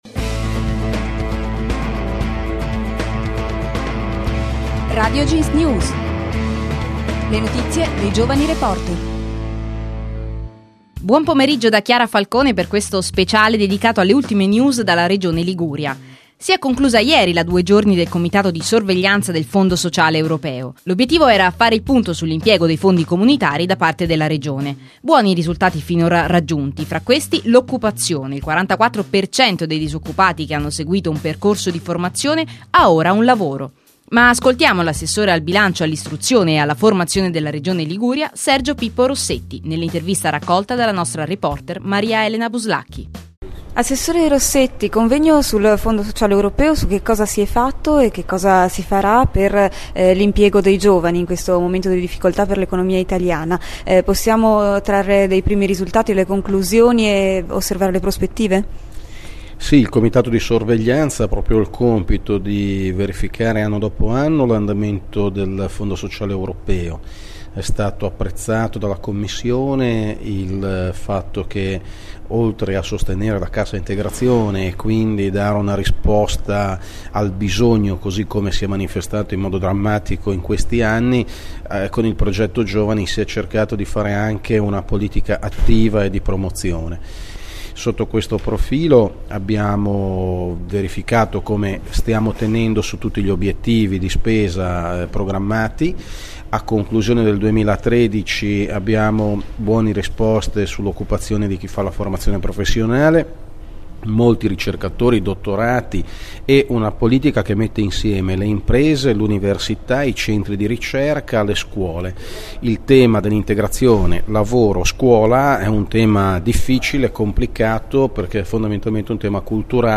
Le notizie dei giovani reporter